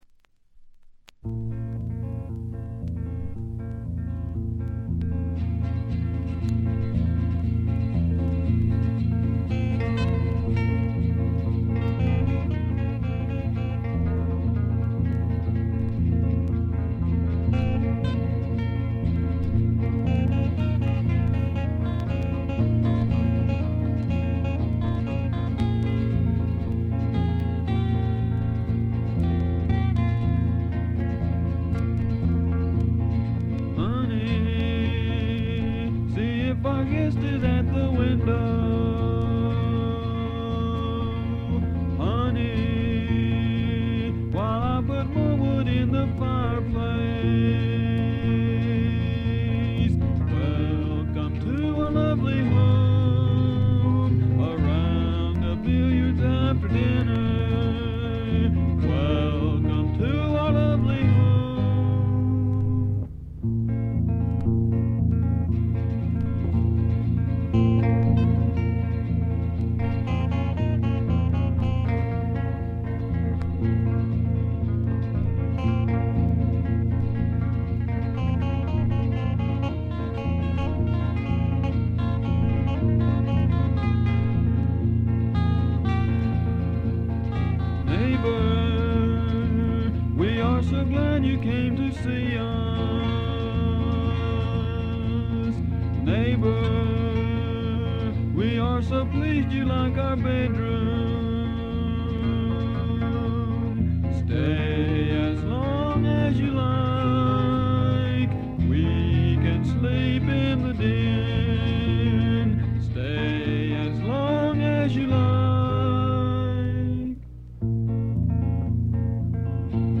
ヴァージニア州の3人組が密かに残したウルトラ・グレイトな自主フォーク名盤で、今世紀に入ってから騒がれ出したレコードです。
音質的にはラジカセより少しはマシってレベルです。
試聴曲は現品からの取り込み音源です。